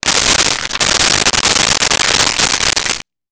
Paper Rip Crumble
Paper Rip Crumble.wav